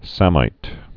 (sămīt, sāmīt)